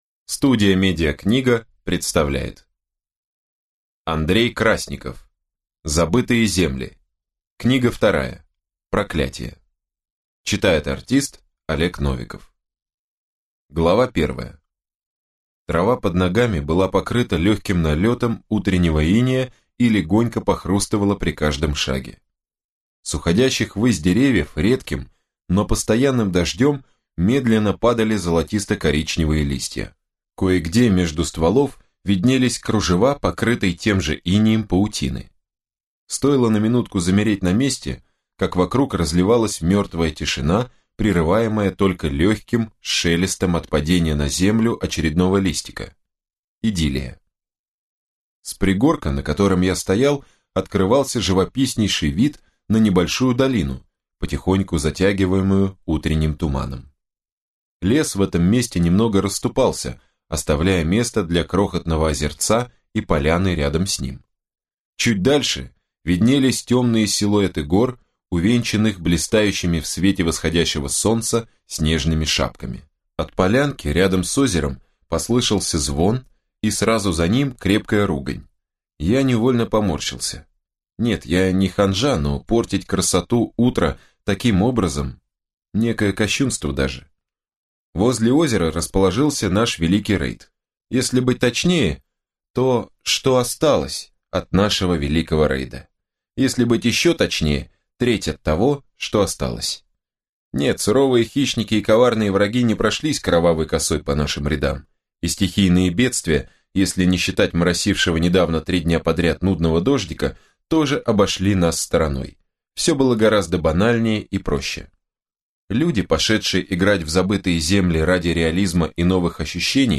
Аудиокнига Проклятие | Библиотека аудиокниг